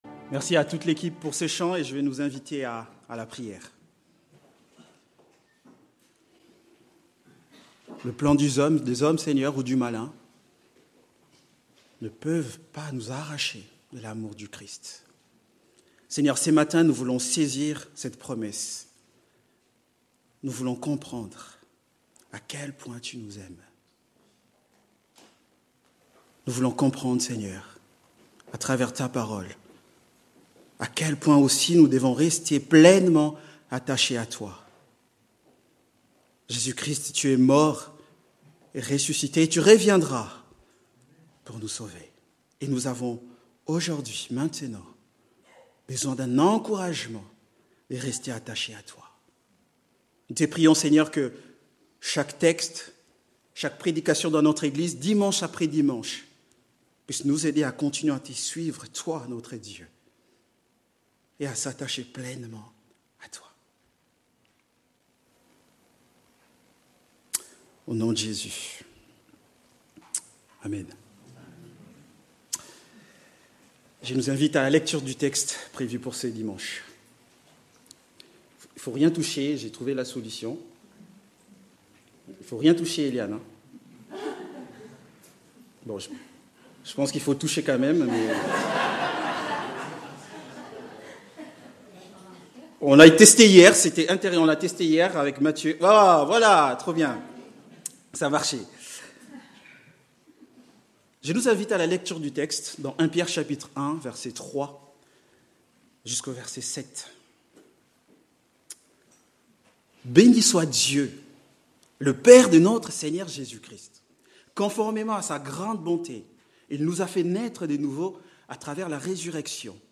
Culte du dimanche 15 mars 2026 – Église de La Bonne Nouvelle